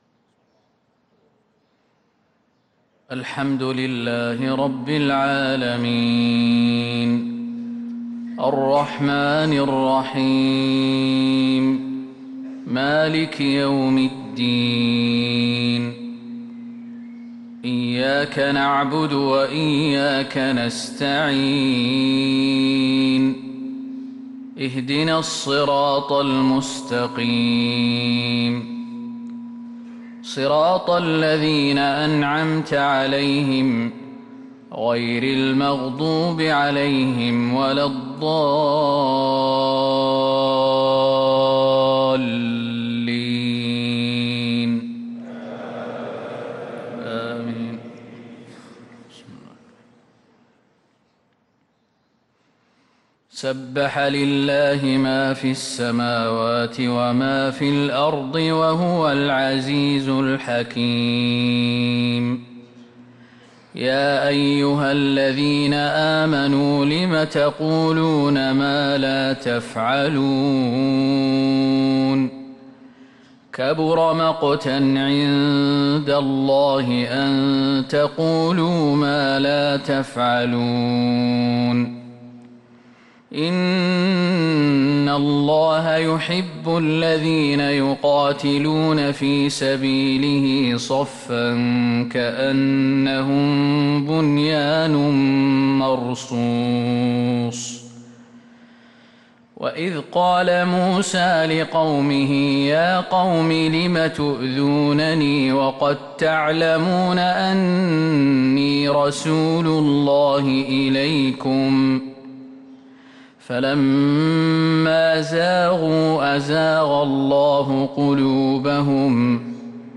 صلاة الفجر للقارئ خالد المهنا 17 ربيع الآخر 1444 هـ